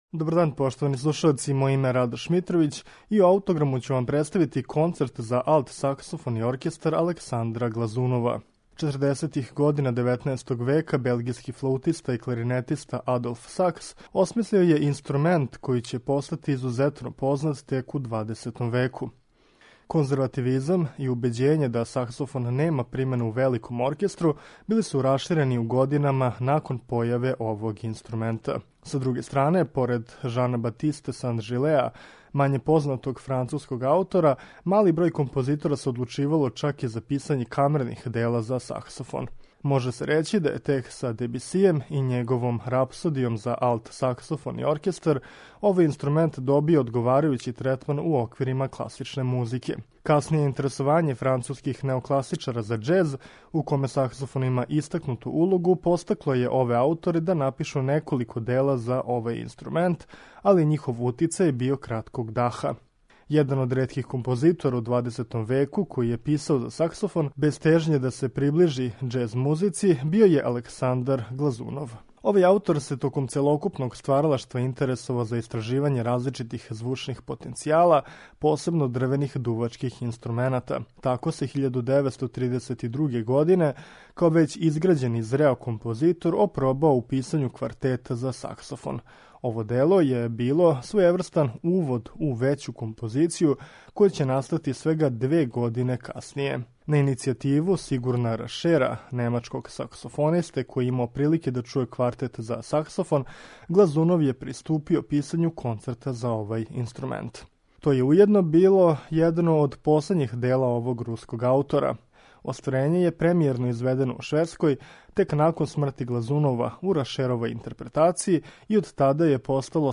Слушаћете Концерт за алт саксофон и оркестар Александра Глазунова
У данашњем Аутограму, Концерт за алт саксофон и оркестар, Александра Глазунова, слушаћете у извођењу Теодора Керкезоса и Филхармонија оркестра, под управом Мартина Брабинса.